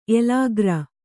♪ elāgra